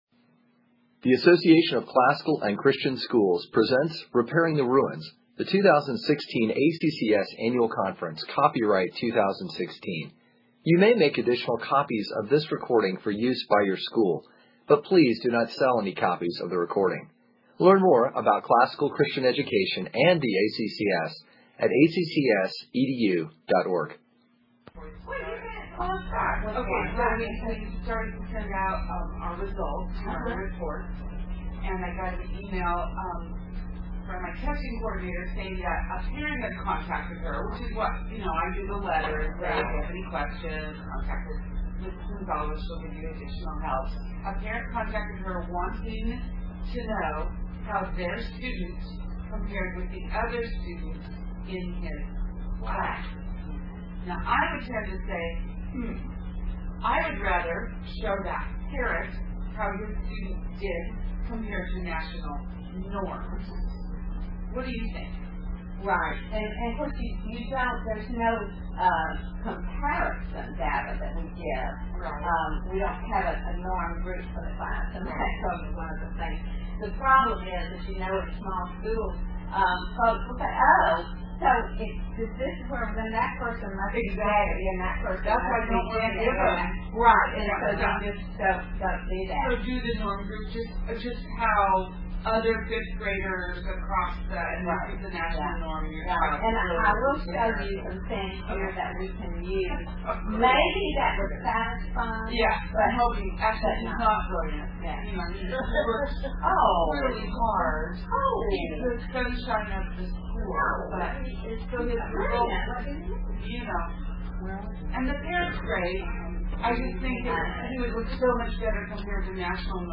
2016 Foundations Talk | 1:22:56 | All Grade Levels, General Classroom